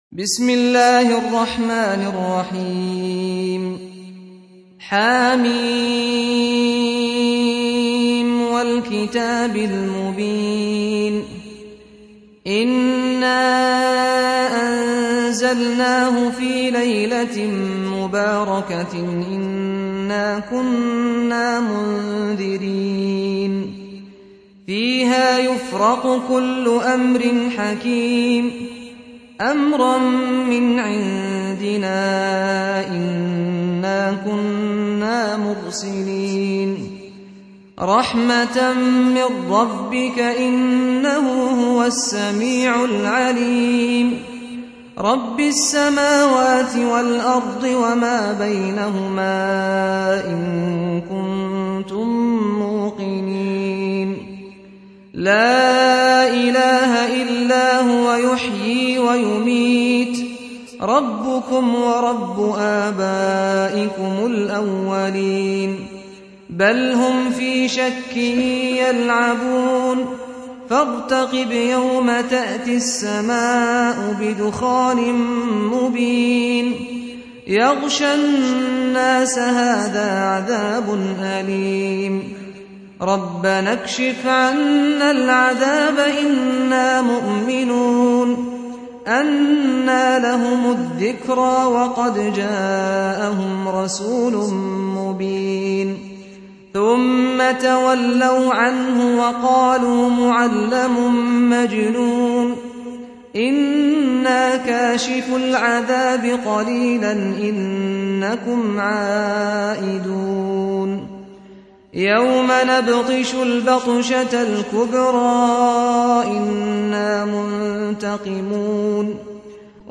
Surah Sequence تتابع السورة Download Surah حمّل السورة Reciting Murattalah Audio for 44. Surah Ad-Dukh�n سورة الدّخان N.B *Surah Includes Al-Basmalah Reciters Sequents تتابع التلاوات Reciters Repeats تكرار التلاوات